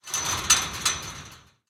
ambienturban_2.ogg